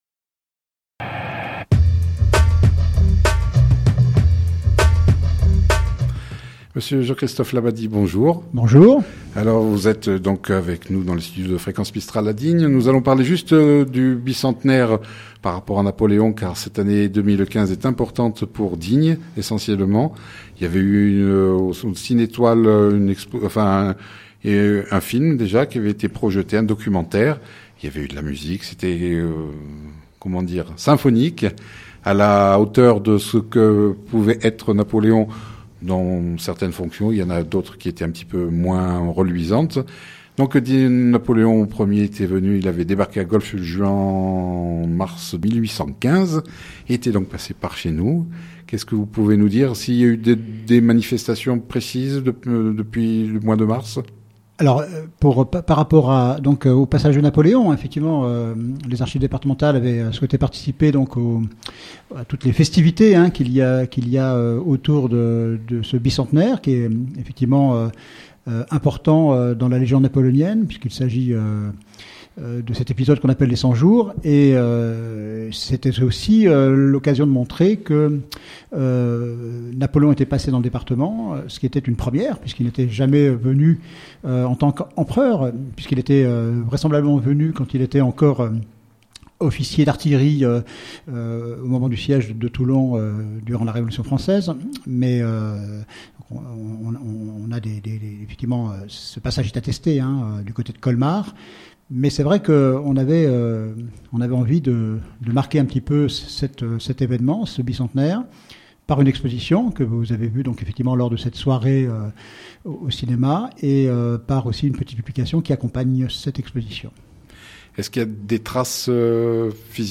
dans nos studios de Fréquence mistral de Digne